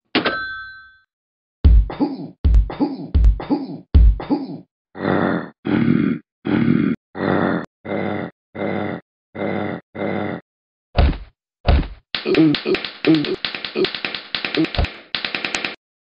Talking Ben Gone Creepy On Phone Call